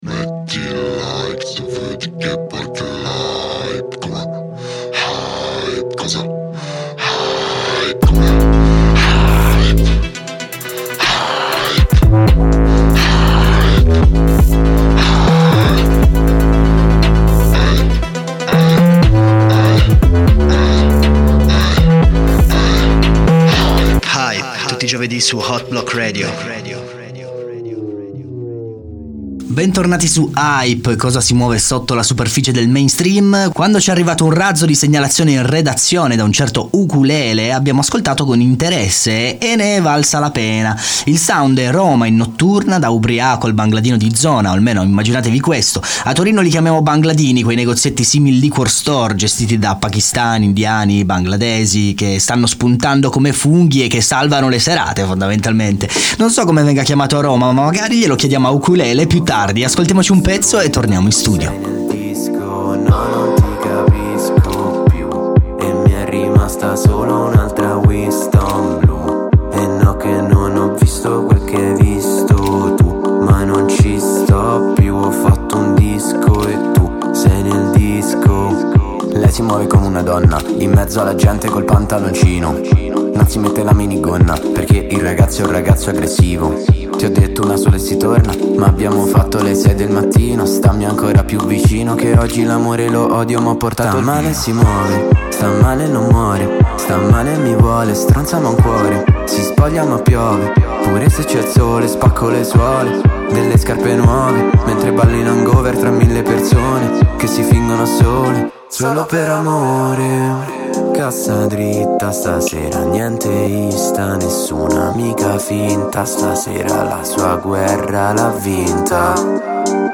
lo abbiamo intervistato su HYPE